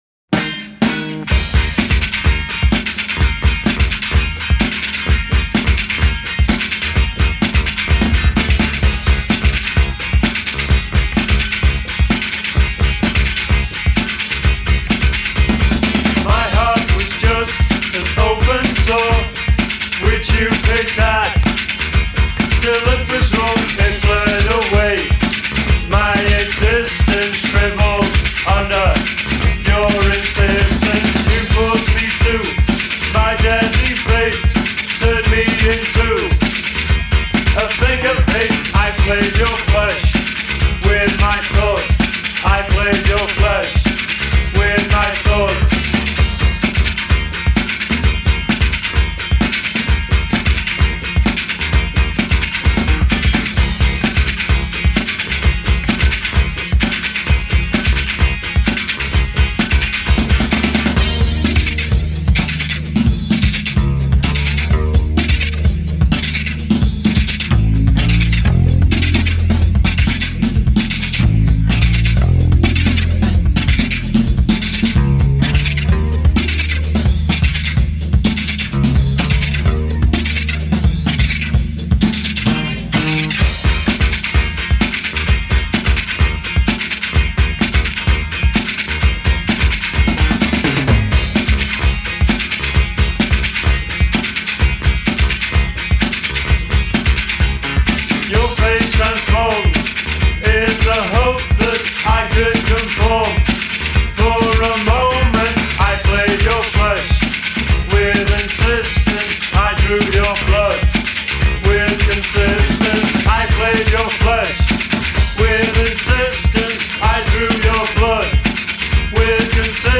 Post-Punk-Funk